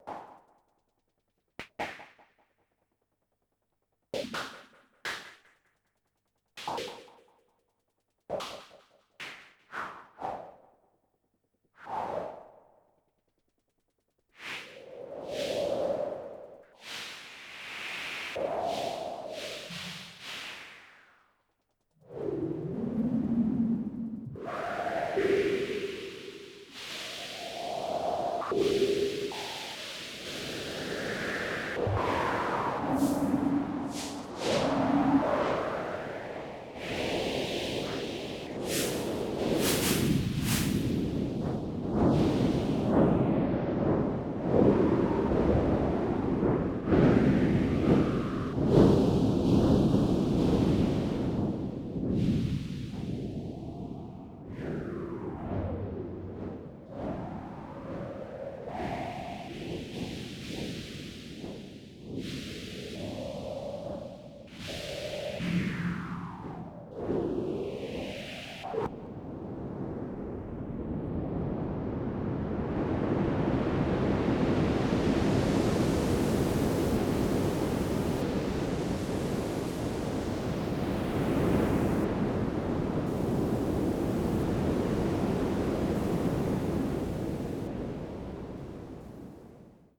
performed live in quadraphonic sound
Buchla 200e
Animoog
which focuses on her live electronic music.